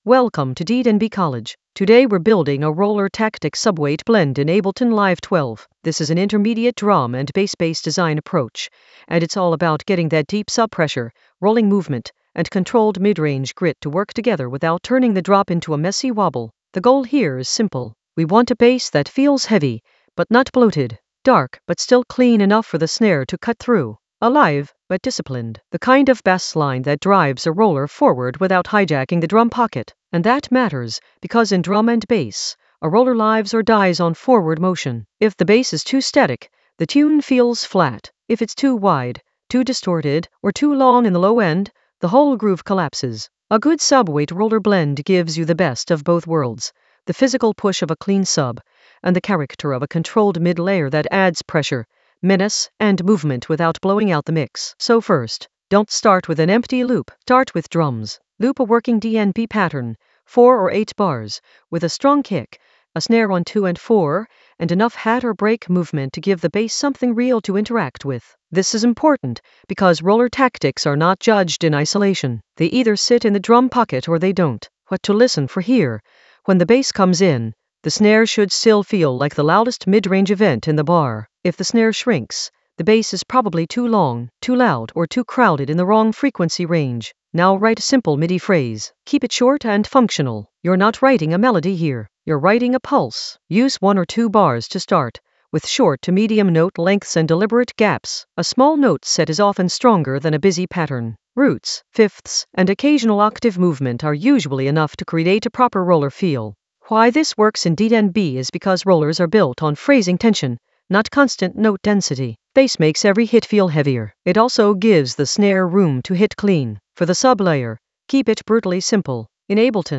An AI-generated intermediate Ableton lesson focused on Roller Tactics approach: a subweight roller blend in Ableton Live 12 in the Sampling area of drum and bass production.
Narrated lesson audio
The voice track includes the tutorial plus extra teacher commentary.